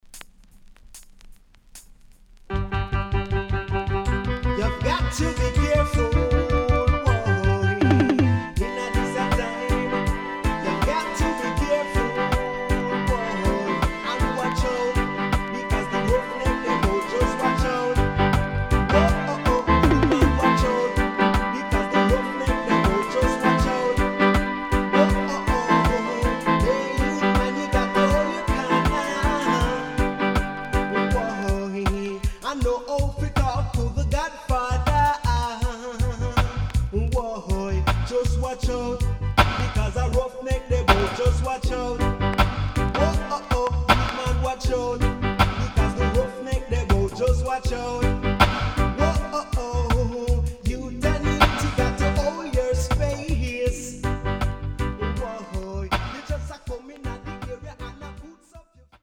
Nice Deejay